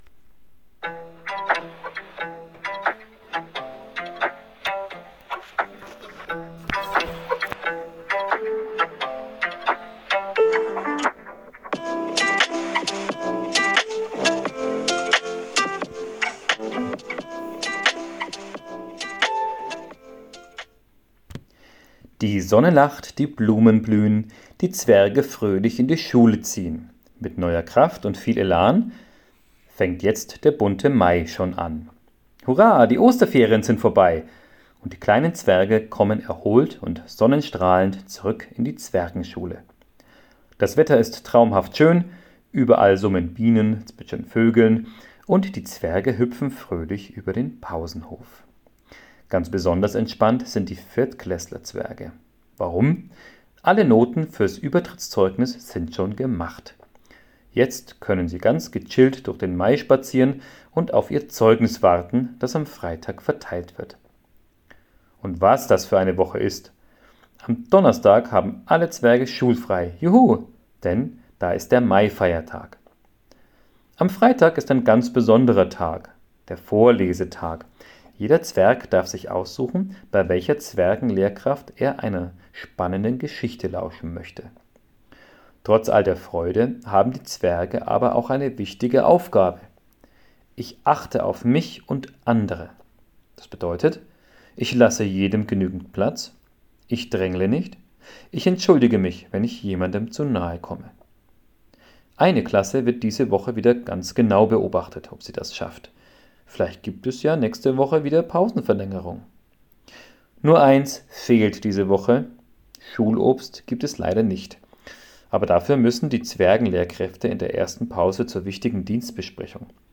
Jeden Montag vor der ersten Pause gibt es über die Lautsprecher die neue Zwergenschulengeschichte. Dort werden die Themen behandelt, die für diese Woche wichtig sind.